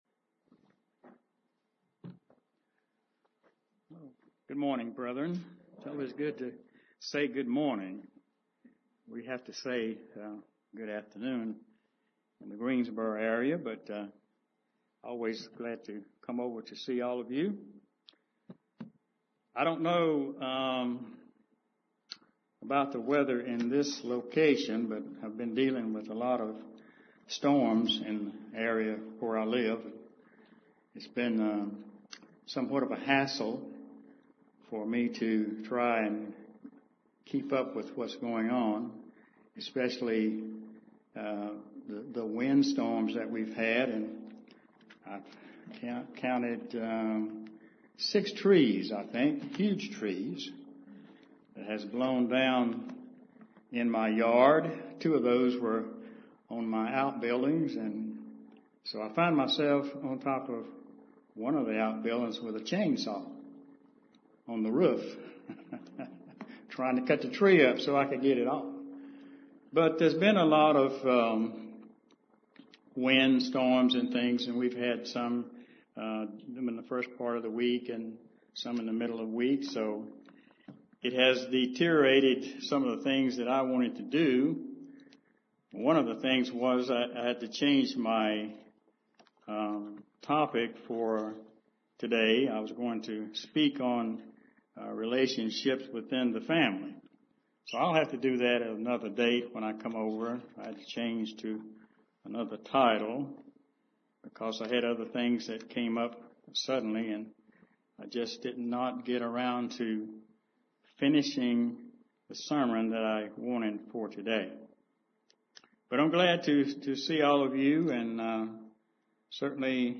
UCG Sermon Studying the bible?
Given in Charlotte, NC